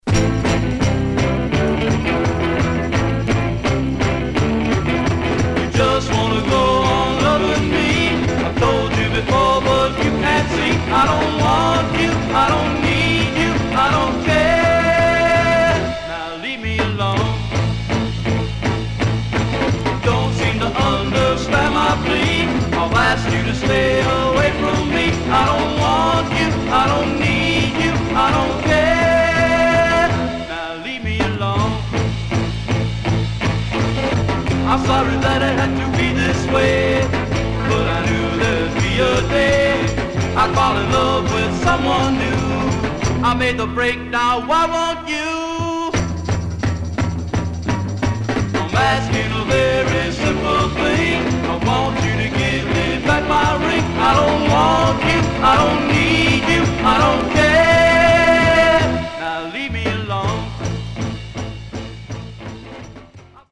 Stereo/mono Mono
Garage, 60's Punk Condition